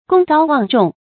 功高望重 gōng gāo wàng zhòng
功高望重发音